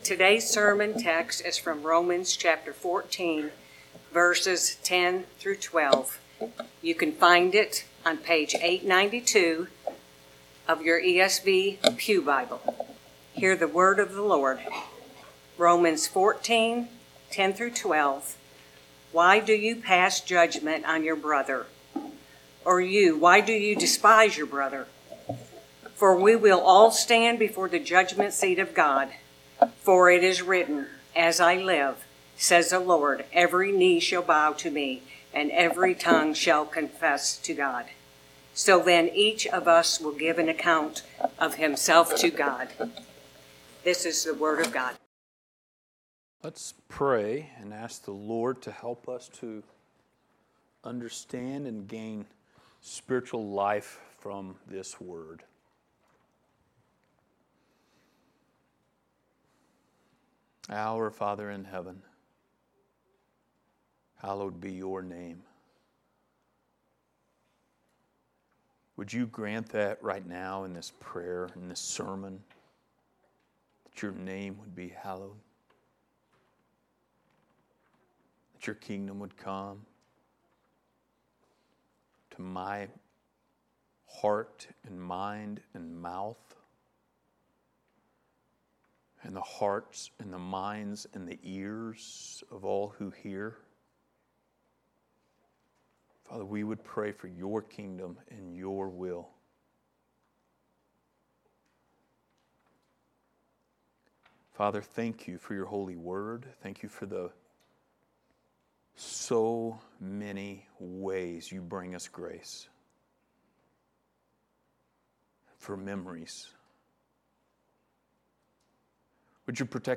Book of Romans Passage: Romans 14:10-12 Service Type: Sunday Morning Related Topics